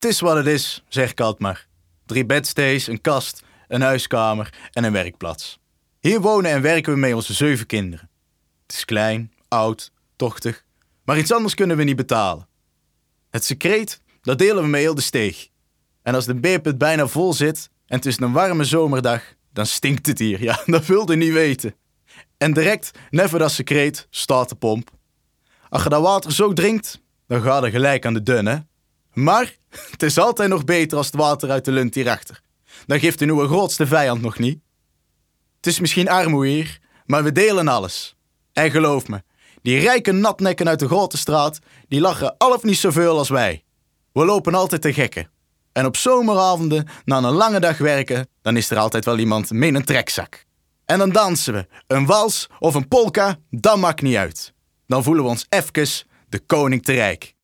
Wij spreken de stemmen in, helemaal is stijl van de historische schoenenindustrie.
De opnames worden weergegeven als een leerrooier & journaal uitzending. Hiermee nemen we bezoekers mee terug in de tijd van de oude schoenenindustrie.